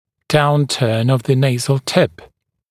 [‘dauntɜːn əv ðə ‘neɪzl tɪp][‘даунтё:н ов зэ ‘нэйзл тип]опущенность кончика носа